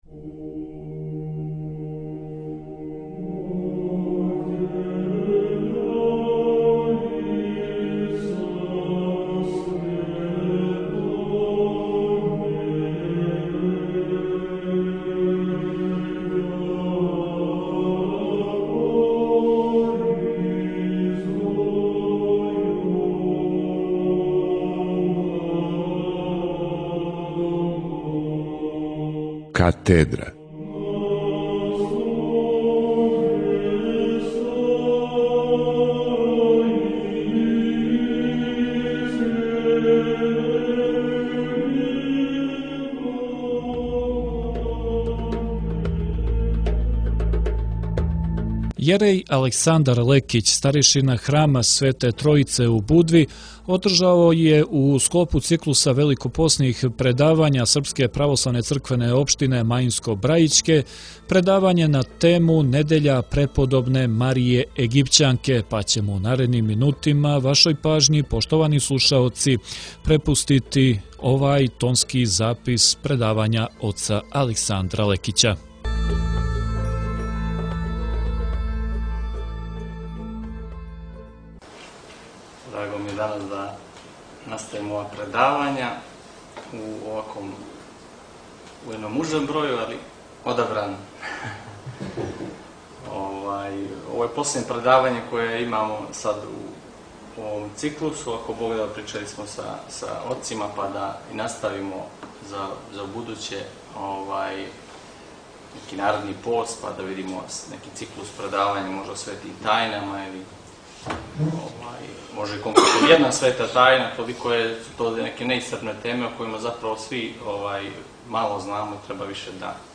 Предавање је одржано у оквиру великопосног циклуса предавања у суботу 20. априла 2024. године у организацији СПЦО маинско-брајићке у сарадњи са СПЦО Стари Град.